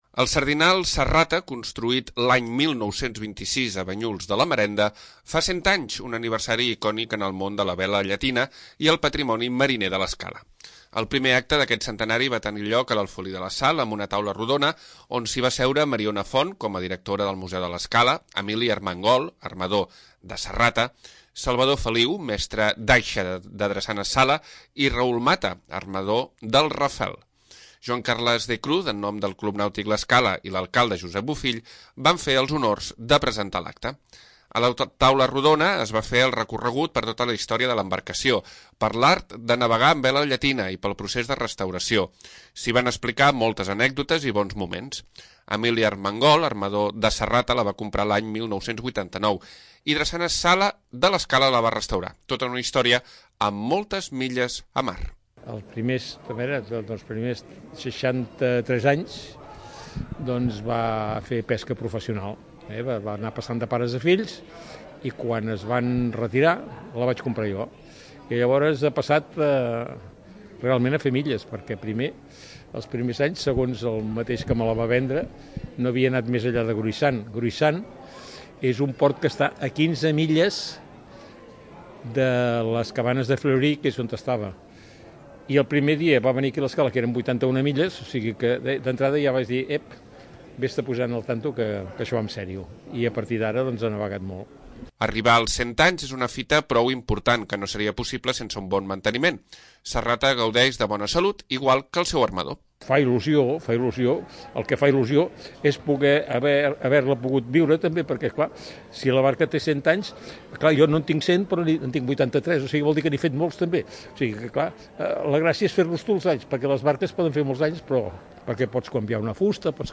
L'Alfolí de la Sal ha acollit una taula rodona en motiu del centenari del sardinal Sa Rata. Historiadors, armadors i mestres d'aixa van posar en relleu el patrimoni de la vela llatina i el valor d'aquesta embarcació amb port a l'Escala.